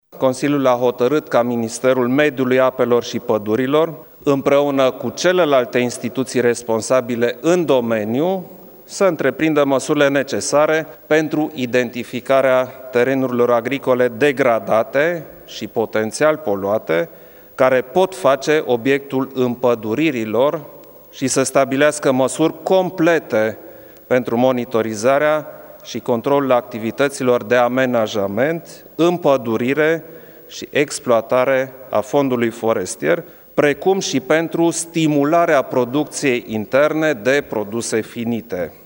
Revine președintele României, Klaus Iohannis:
iohannis-impaduriri.mp3